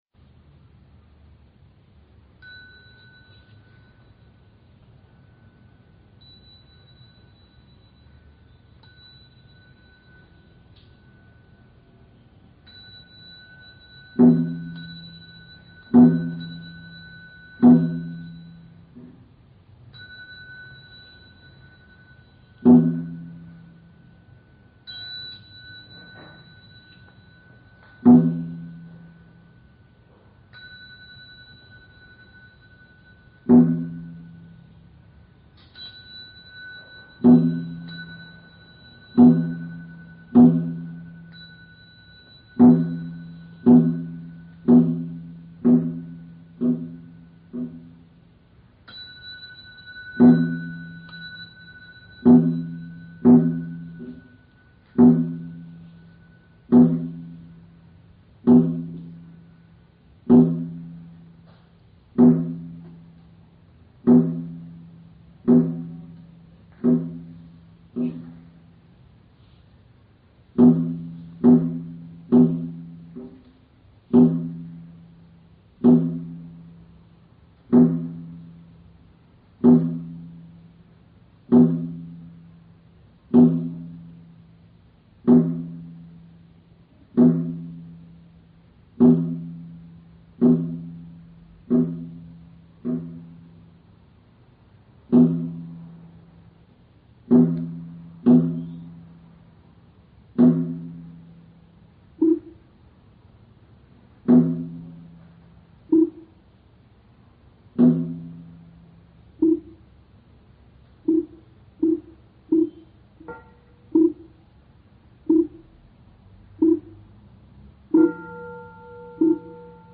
早课诵念（净宗课诵）--未知 经忏 早课诵念（净宗课诵）--未知 点我： 标签: 佛音 经忏 佛教音乐 返回列表 上一篇： 语加持--希阿博荣堪布 下一篇： 普庵咒（唱诵）--文殊院 相关文章 赞佛偈--佛光山中国佛教研究院 赞佛偈--佛光山中国佛教研究院...